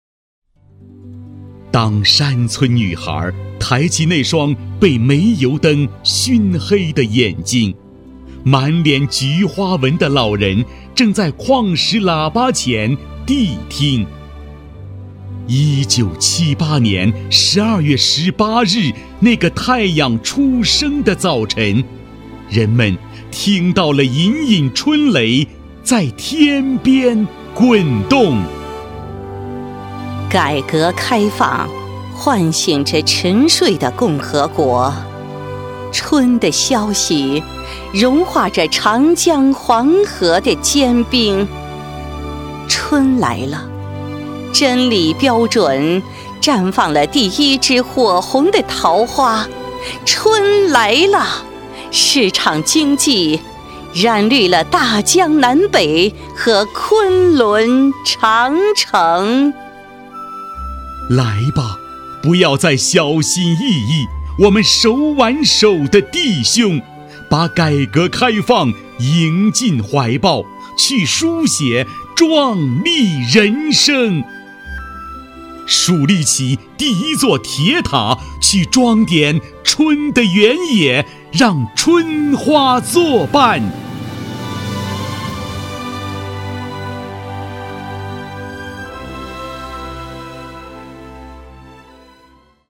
男国162_其他_对播_专题女国75.mp3